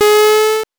powerup_22.wav